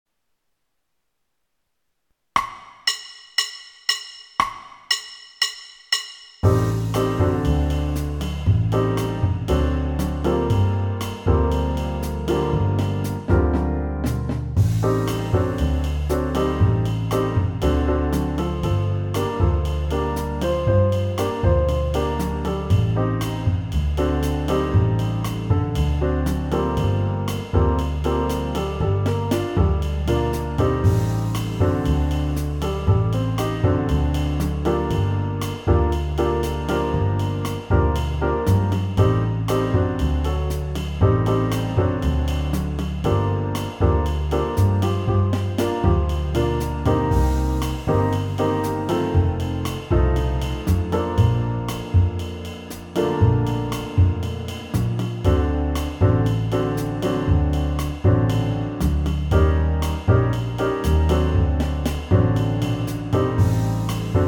BACKING TRACKS: